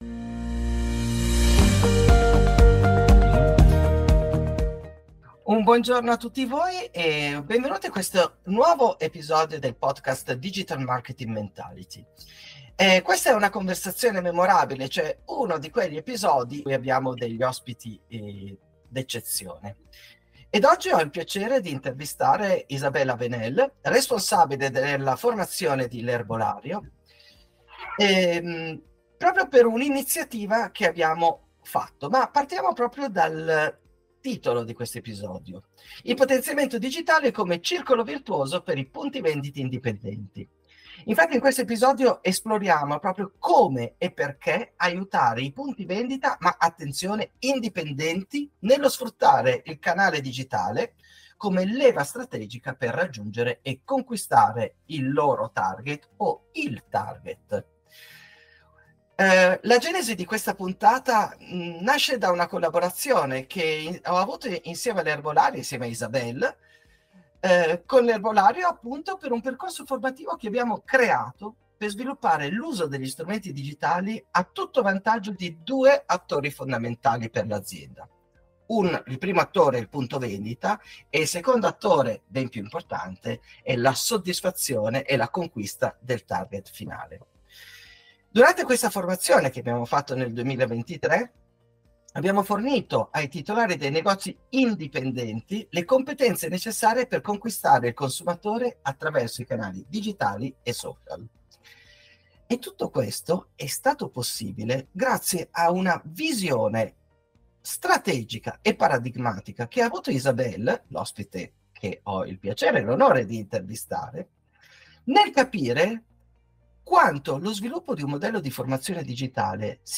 Esempi di Retail Marketing Efficace: Intervista L'ERBOLARIO